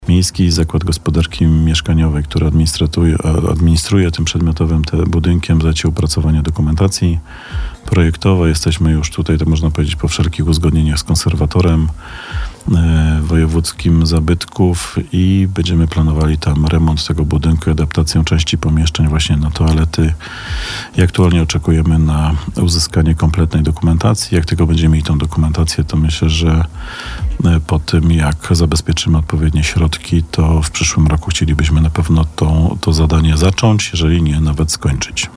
O publicznym WC przy ulicy Łukowej samorządowiec opowiedział na naszej antenie, uczestnicząc w audycji Gość Radia Bielsko.